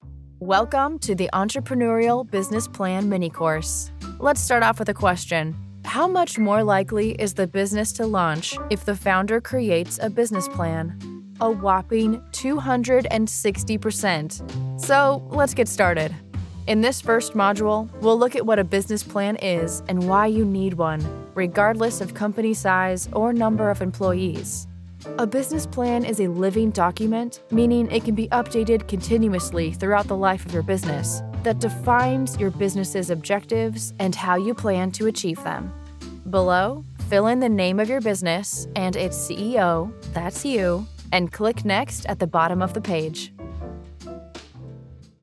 Natürlich, Urban, Warm
E-learning